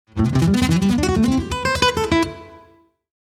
As before, the same picking pattern is utilized to add continuity between the forms.
Here’s the full arpeggio played at tempo.
copy-of-am9-full-120.mp3